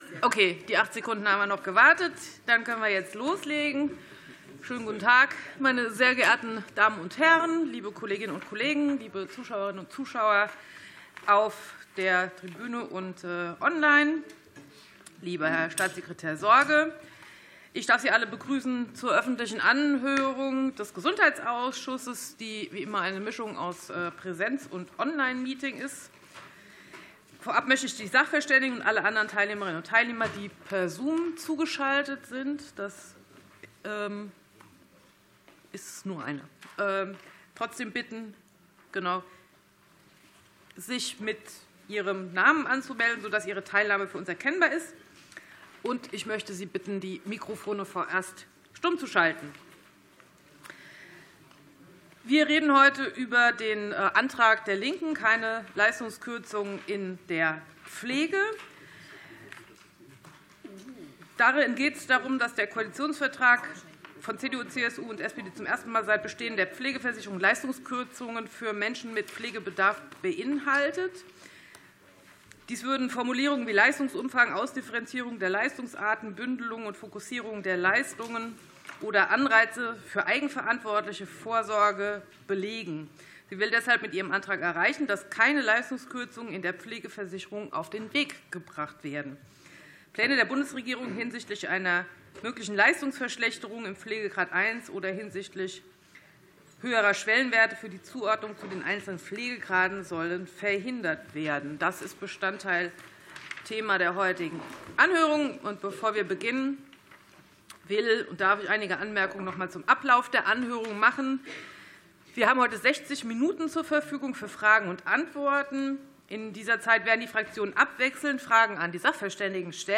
Anhörung des Ausschusses für Gesundheit